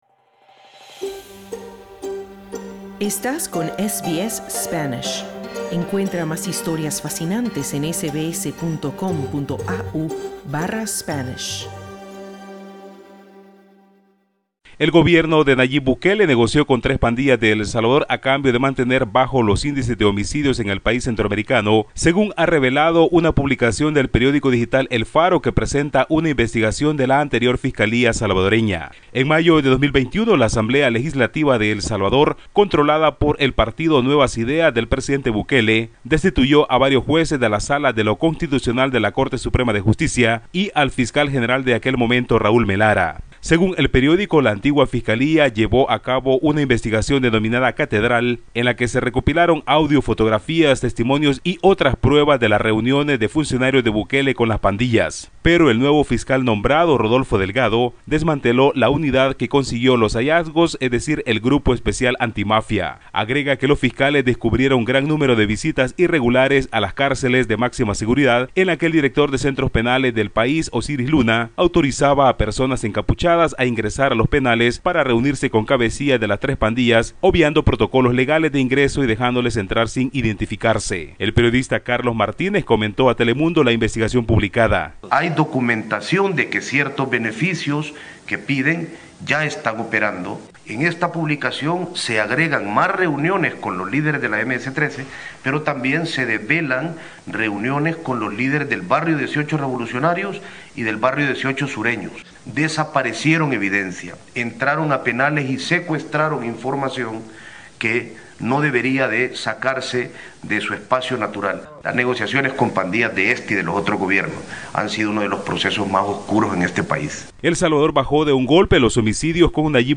Una investigación periodística en El Salvador revela que el gobierno de Nayib Bukele sostuvo en 2020 negociaciones con las pandillas para reducir las cifras de homicidios e intentó esconder las evidencias. Escucha el informe del corresponsal de SBS Spanish en Centroamérica